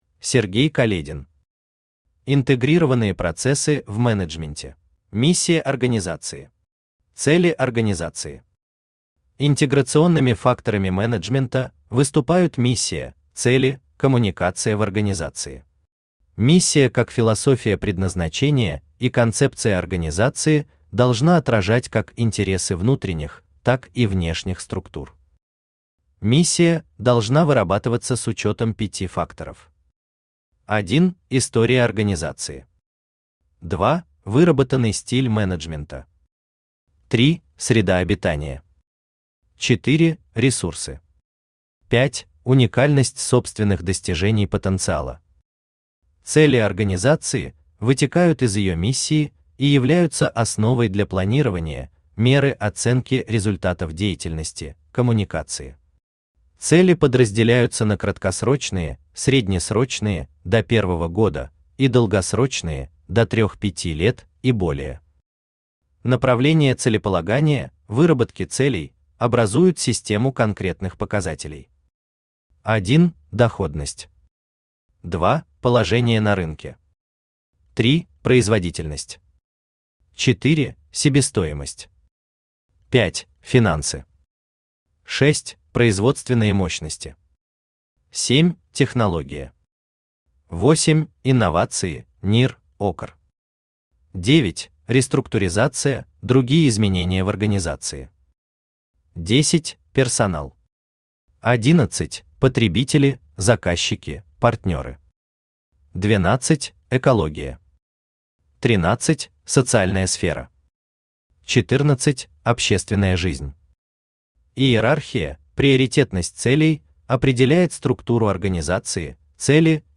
Аудиокнига Интегрированные процессы в менеджменте | Библиотека аудиокниг
Aудиокнига Интегрированные процессы в менеджменте Автор Сергей Каледин Читает аудиокнигу Авточтец ЛитРес.